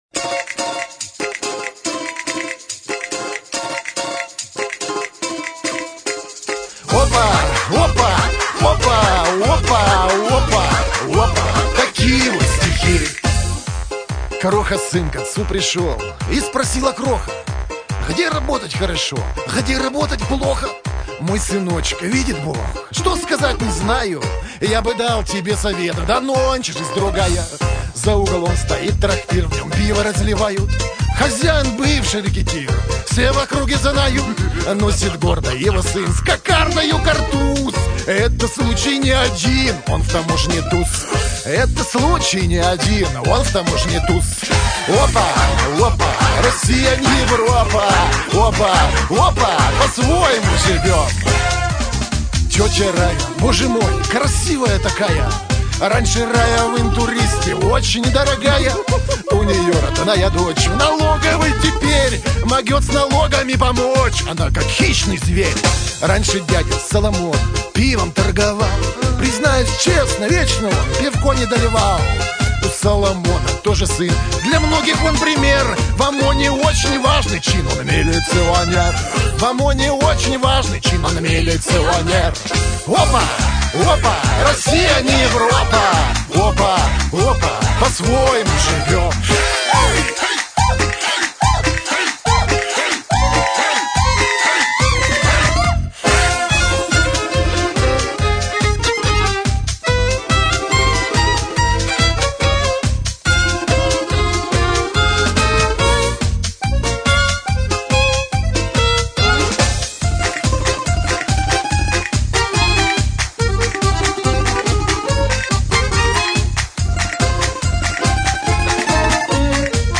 исполняемых в стиле «русский шансон».